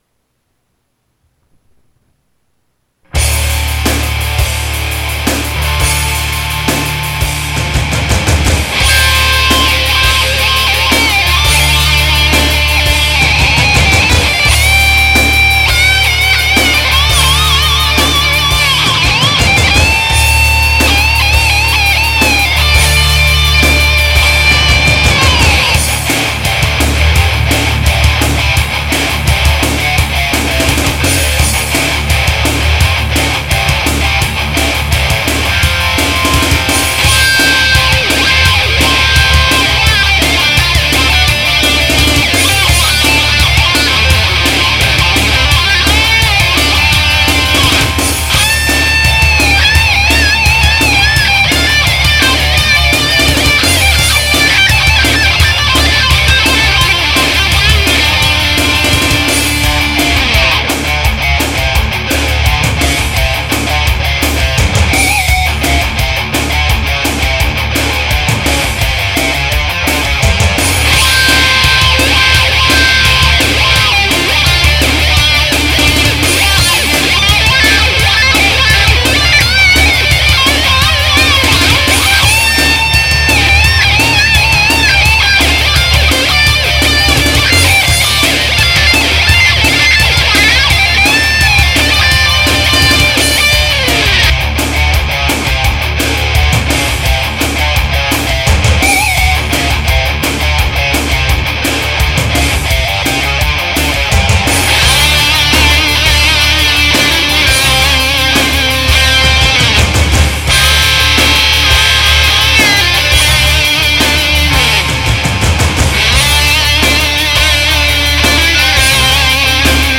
Drums: Dr. Rhythm Drum Machine